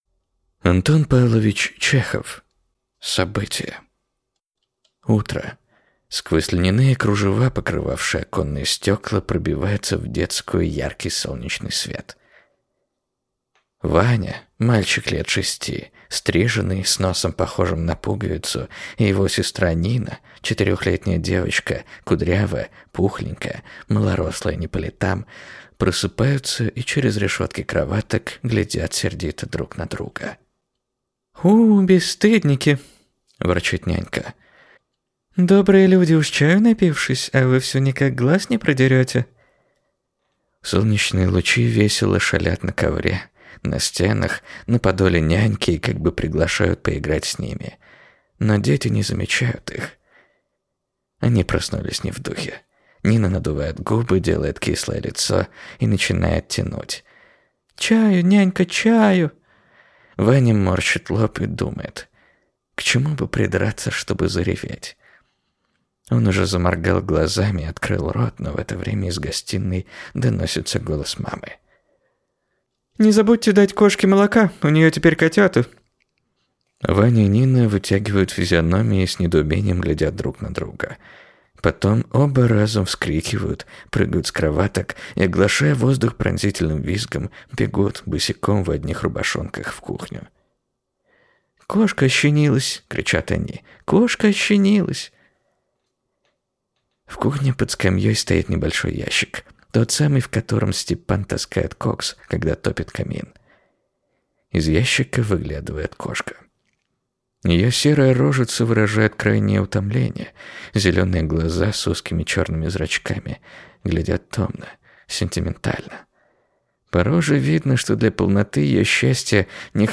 Аудиокнига Событие | Библиотека аудиокниг